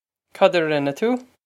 Pronunciation for how to say
Cod ah rin-eh too?
This is an approximate phonetic pronunciation of the phrase.